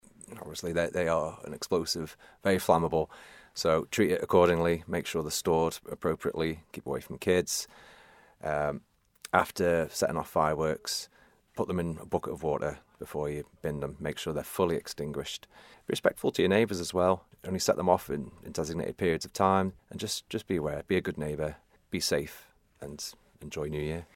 Leading fireman